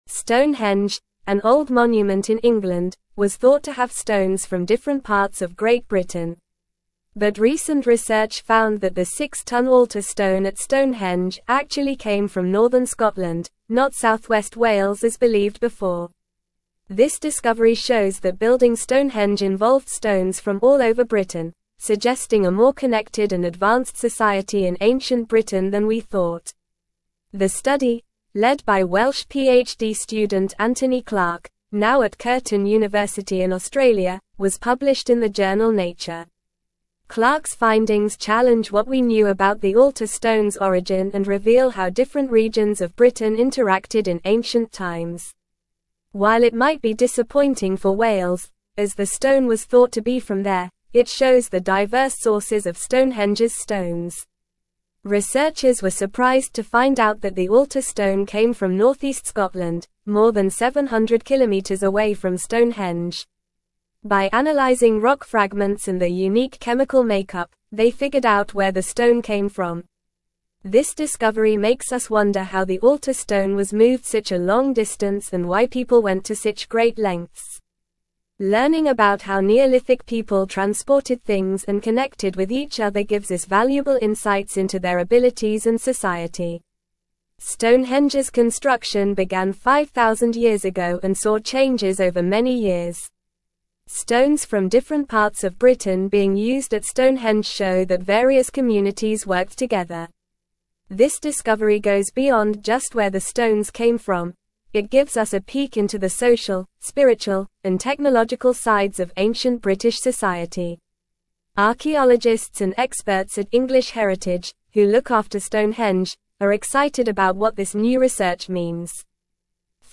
Normal
English-Newsroom-Upper-Intermediate-NORMAL-Reading-Stonehenge-Altar-Stone-Originates-from-Scotland-Not-Wales.mp3